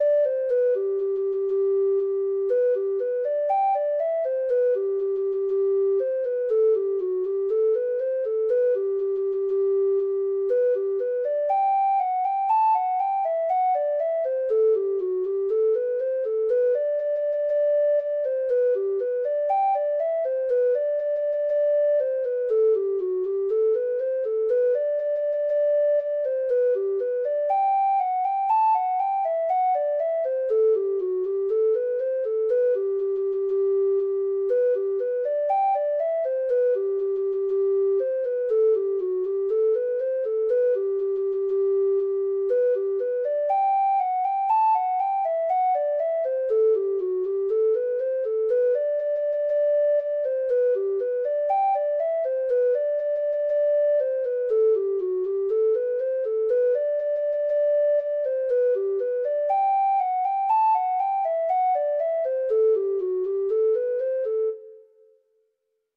Free Sheet music for Treble Clef Instrument
Reels
Irish